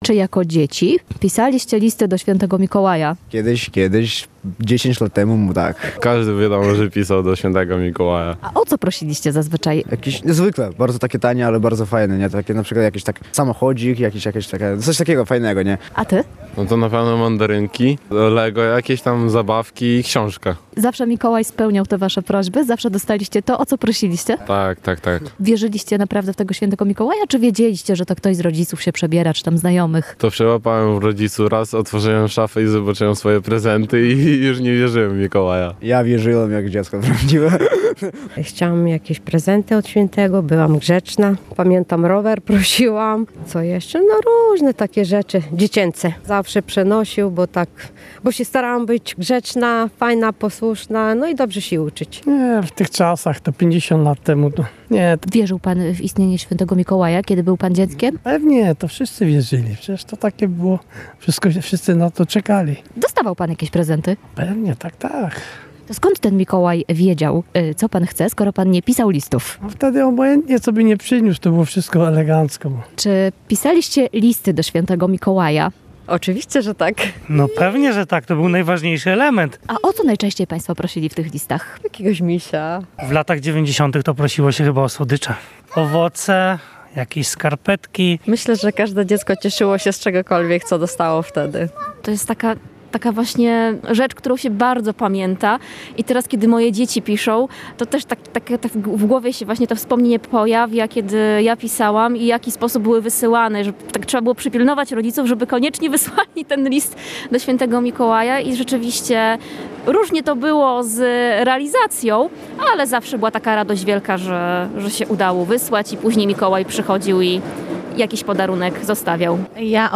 [SONDA] Mikołajki już jutro.